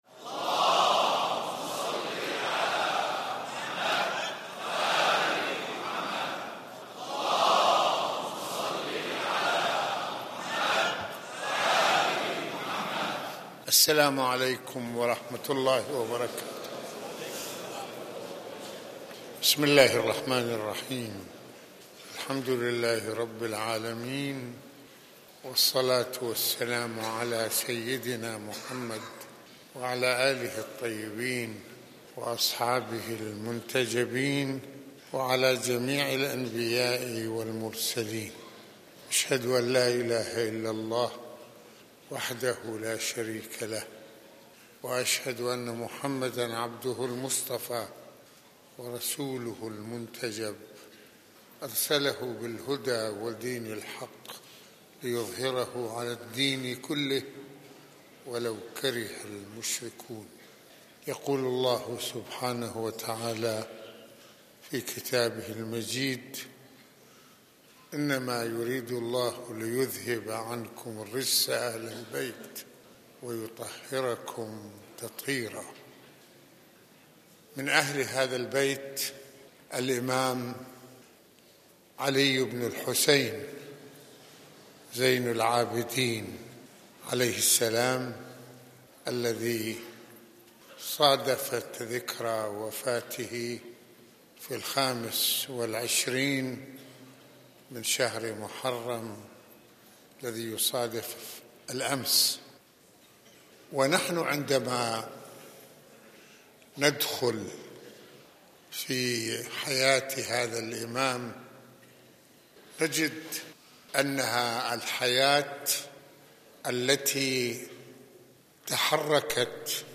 خطبة الجمعة
مسجد الإمامين الحسنين (ع)